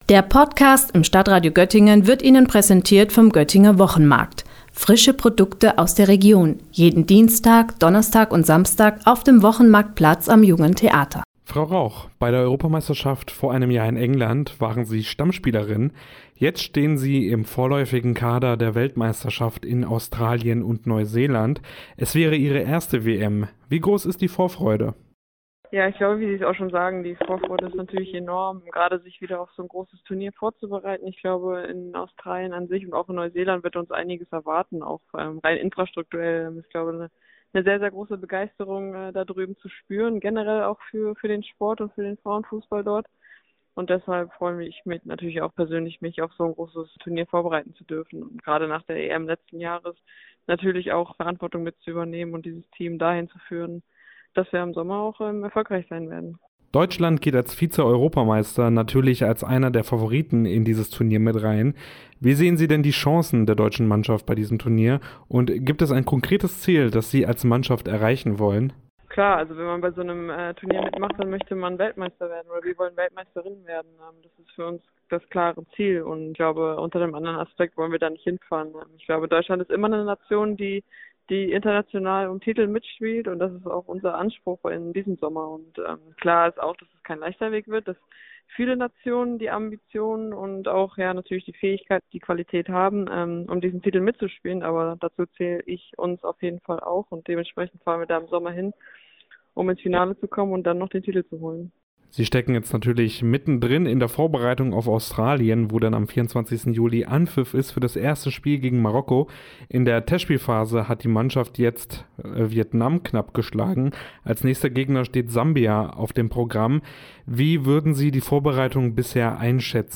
Beiträge > „Wir wollen Weltmeisterinnen werden“ – Fußballerin Felicitas Rauch im Interview - StadtRadio Göttingen
Sie hat uns ein kurzes Interview zur WM gegeben.